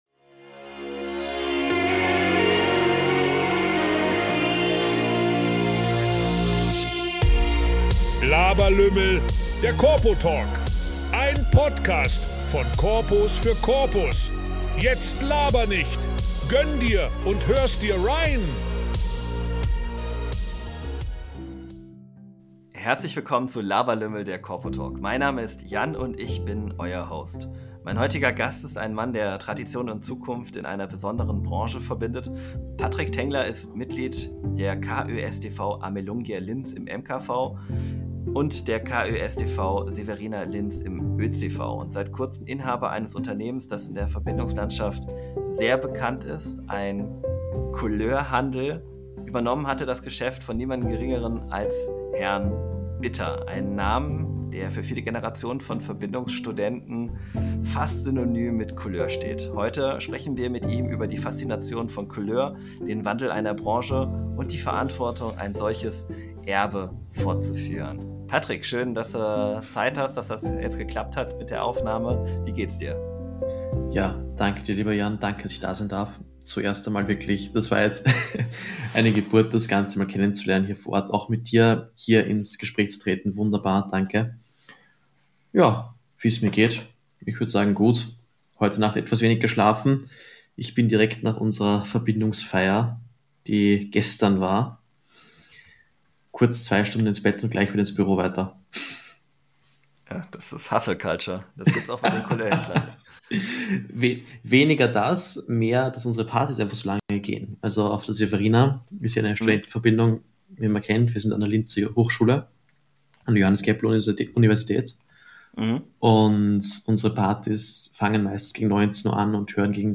Ein Gespräch über Verantwortung, Handwerk, Szene, Wandel und die Zukunft eines besonderen Kulturbereichs.